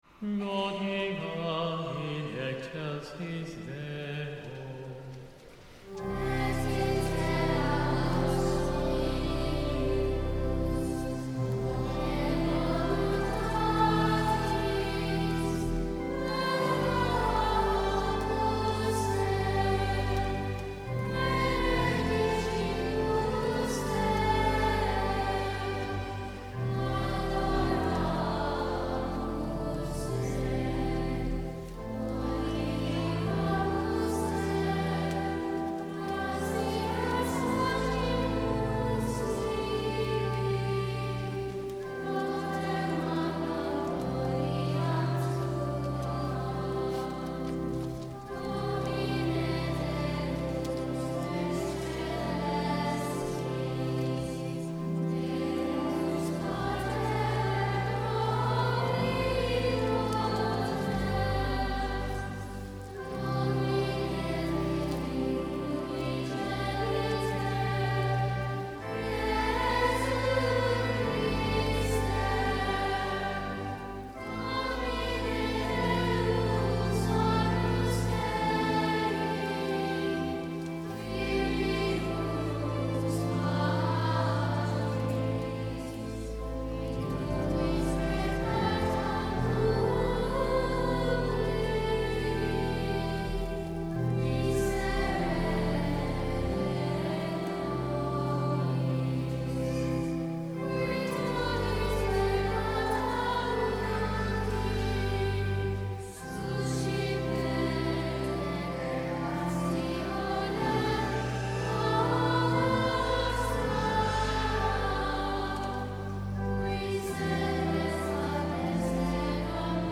Mp3 Download • Live Rec. (“Gloria VIII – Missa de Angelis”)
organ.
Gloria-de-Angelis-Childrens-Choir.mp3